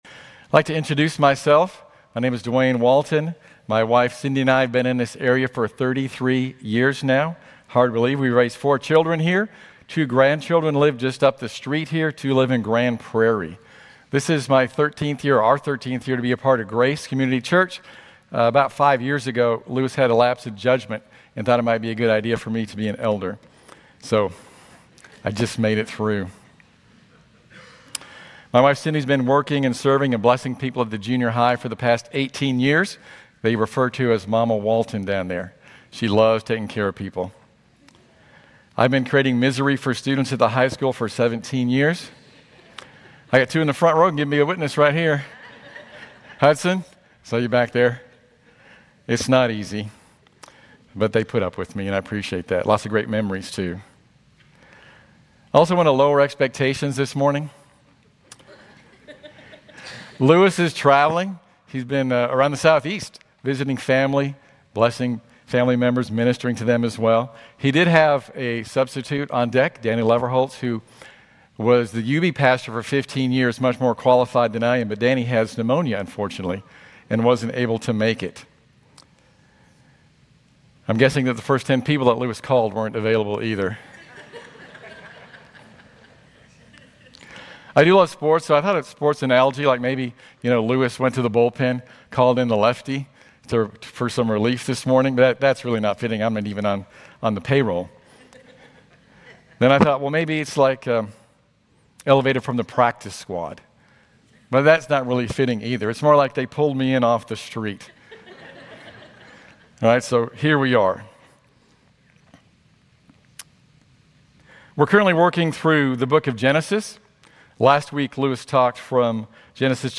Grace Community Church Lindale Campus Sermons Genesis 11 - Tower of Babel Oct 07 2024 | 00:24:01 1x 00:00 / 00:24:01 Subscribe Share RSS Feed Share Link Embed